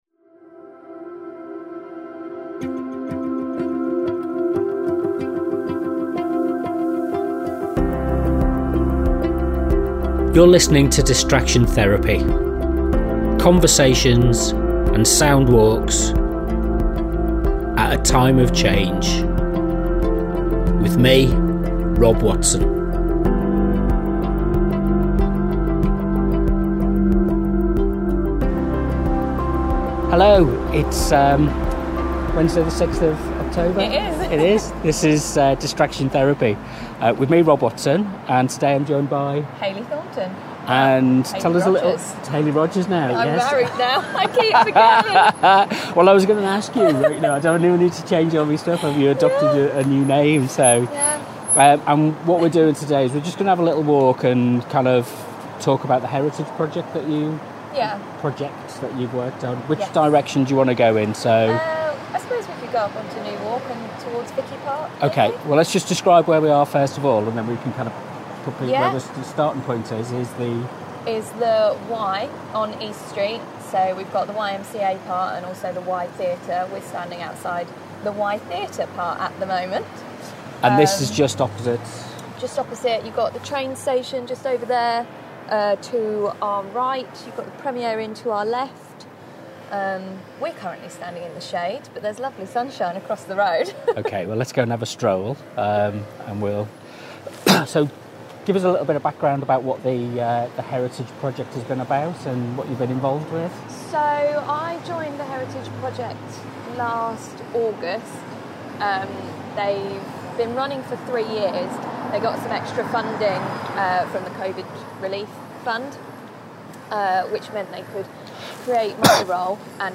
Distraction Therapy 048 – Leicester Heritage Walk